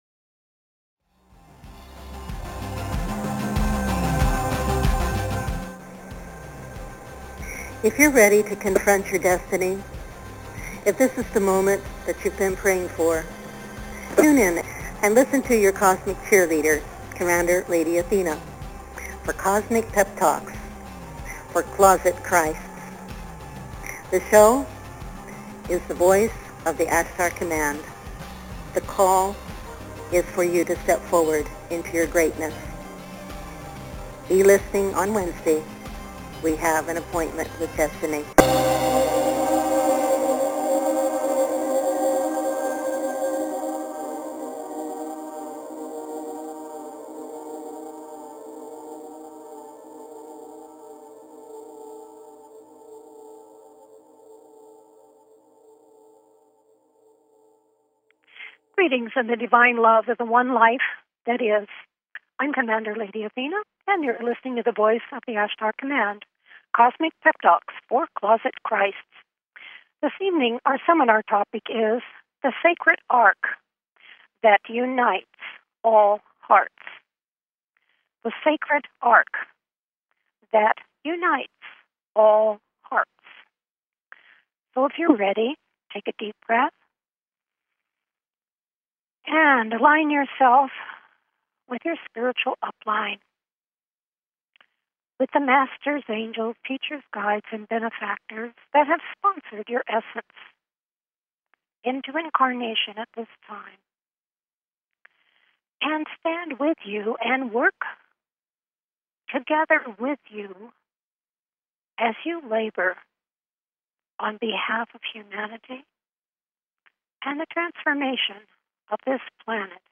Talk Show Episode, Audio Podcast, The_Voice_of_the_Ashtar_Command and Courtesy of BBS Radio on , show guests , about , categorized as
Our BBS radio shows are quantum awareness Intensives especially designed to provide deeper insight into your life as an immortal Divine Whole Light Being, your Soul purpose and how to integrate that into your daily life.